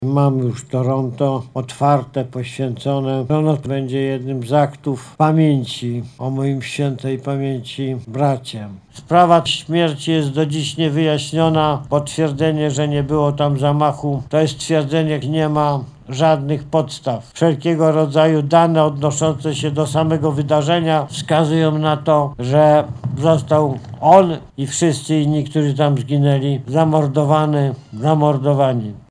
Stosowną uchwałę parczewscy radni podjęli w listopadzie ubiegłego roku, dziś uroczyście nadano imię a wstęgę przeciął Jarosław Kaczyński, który podkreślał, swoją wiarę, że w Smoleńsku doszło do zamachu: